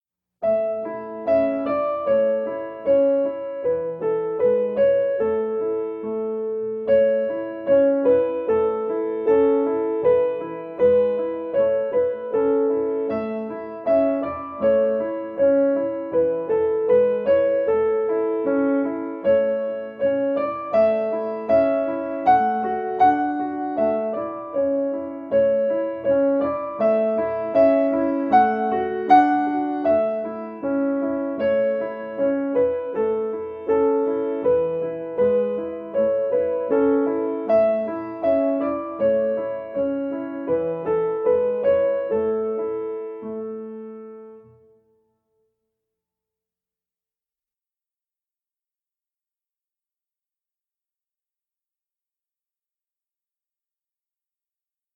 Practice Recordings
Go Tell Aunt Rhody--Violin Slow
go_tell_aunt_rhody__a__slow.mp3